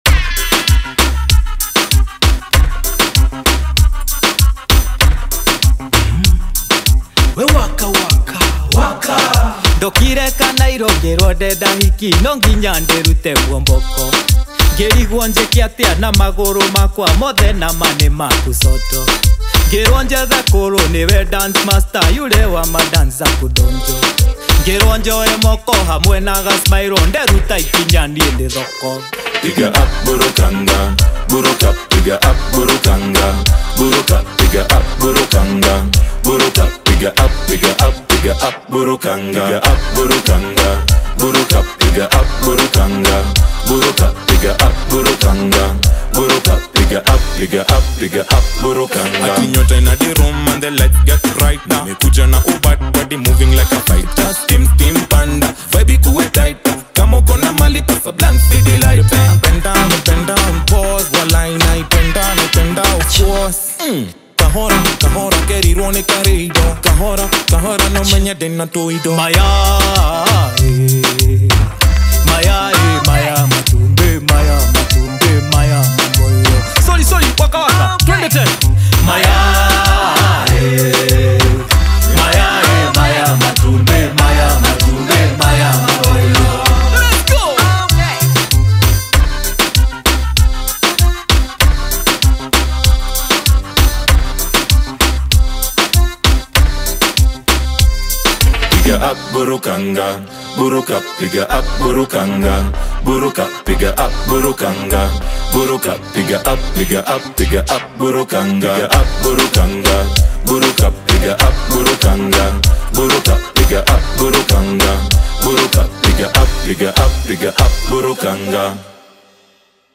• Genre: Afrobeat / African Contemporary
• High-quality Afrobeat production